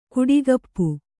♪ kuḍigappu